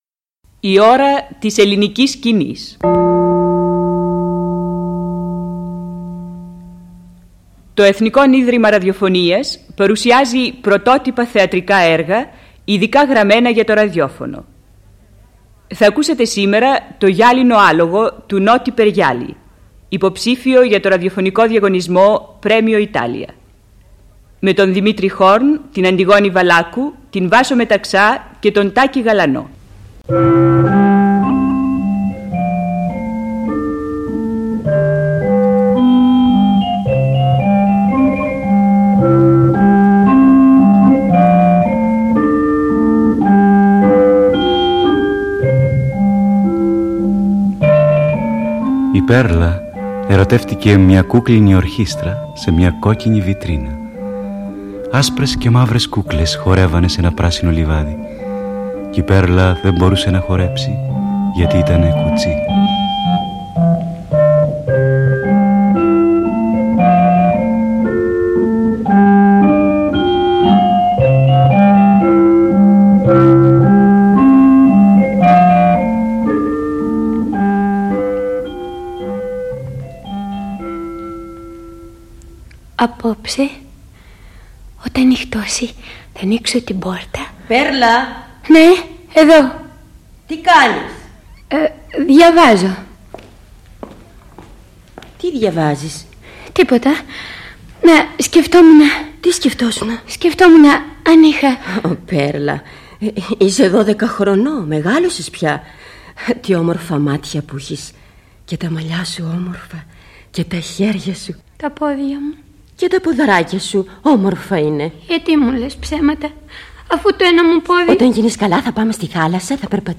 ραδιοφωνικά θεατρικά έργα